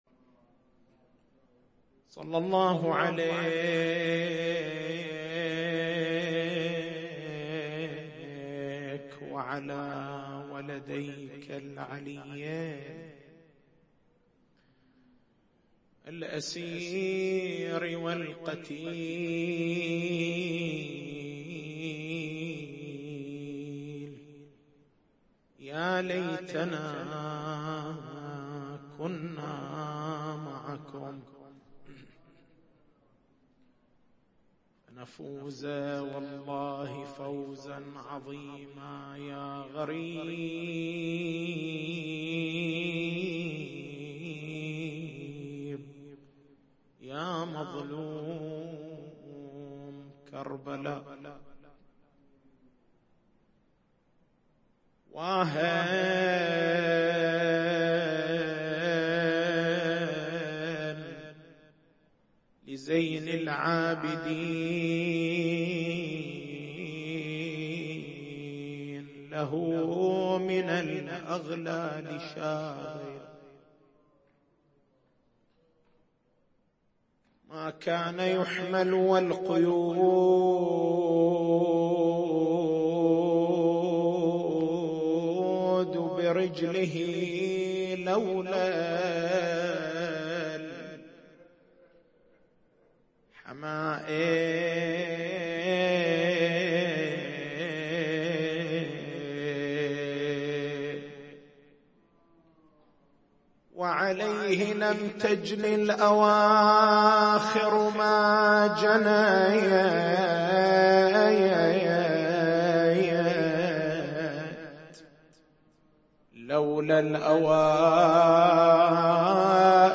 تاريخ المحاضرة: 26/01/1437 نقاط البحث: ما هي قيمة الخشوع الصلاتيّ عند الإمام زين العابدين (ع)؟